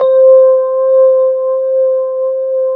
FEND1L  C4-L.wav